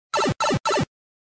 Tesla Lock Sounds & Chimes Collection: Movies, Games & More - TeslaMagz
Mario Pipe.wav